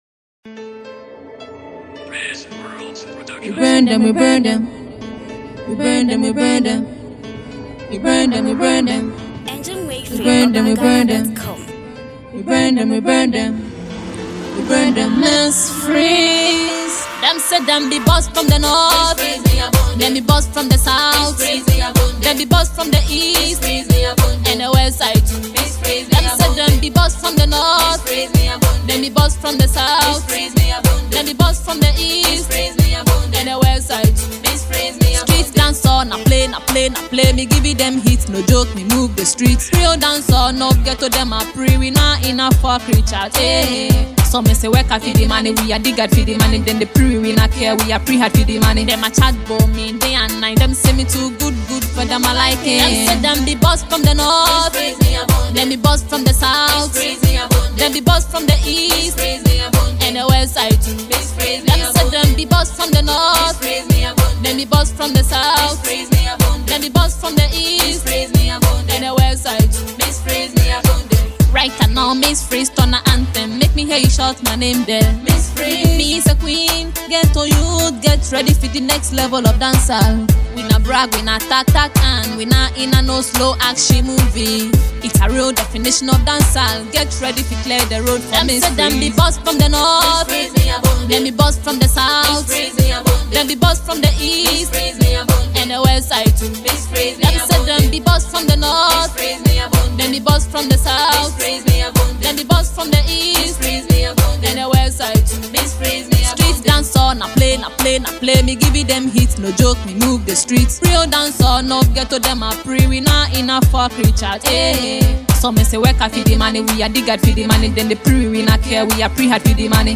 Reggae/Dancehall
dancehall songstress